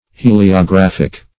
Heliographic \He`li*o*graph"ic\
(h[=e]`l[i^]*[-o]*gr[a^]f"[i^]k), a. (Astron.)